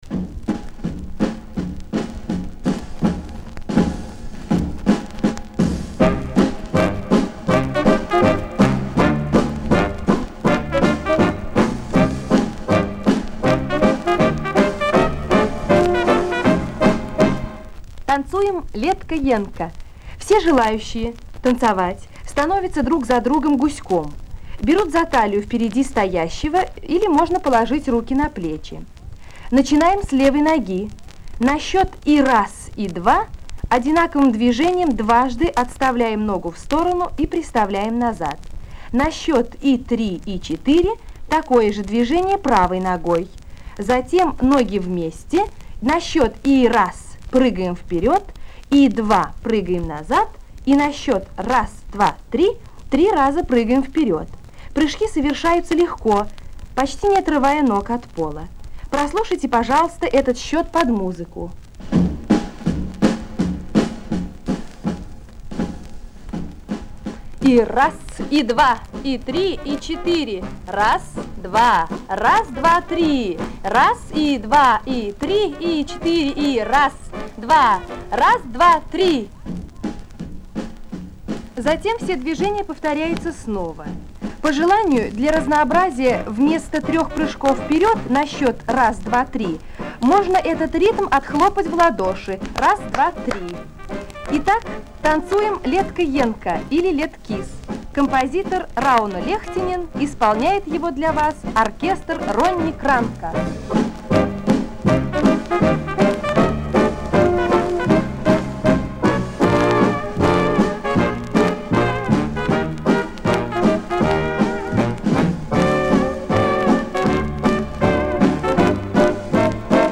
Финляндия танцует леткис, или летка-енкка, - модернизированный народный танец енкка, - уже четыре года.